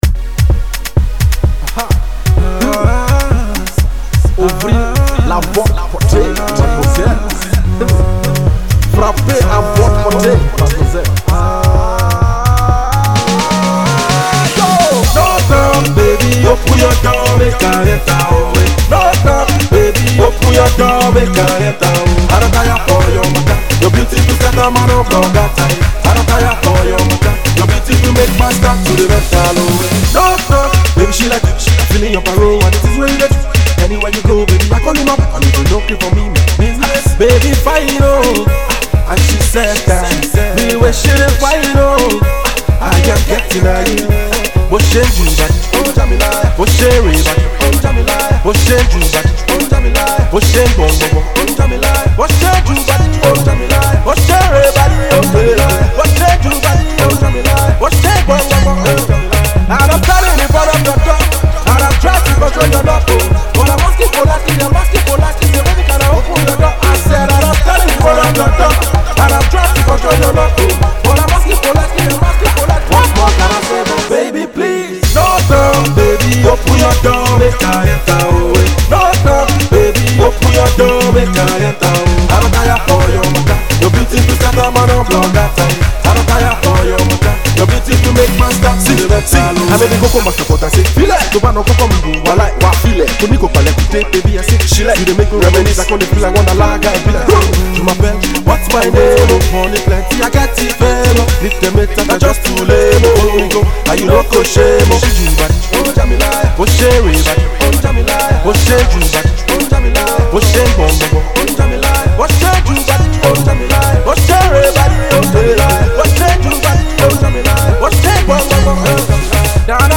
Pop, Rap